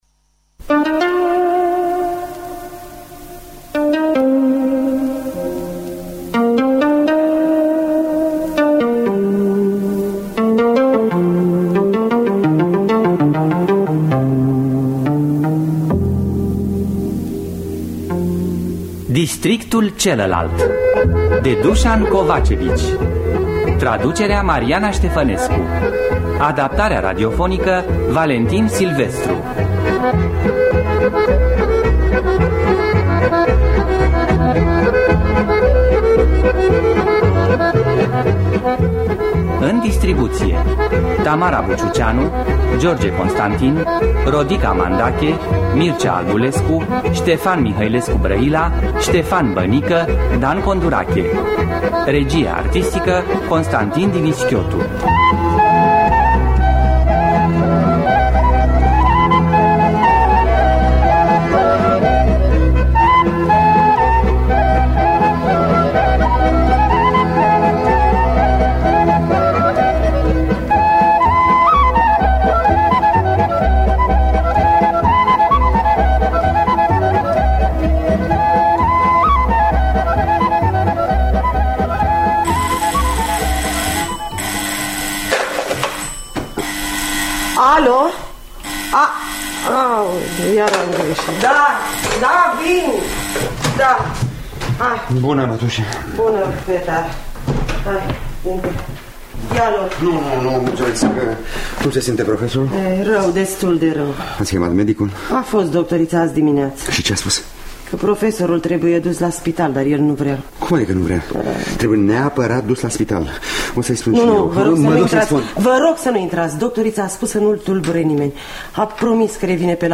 Adaptarea radiofonică de Valentin Silvestru.
Înregistrare din anul 1990 (17 septembrie).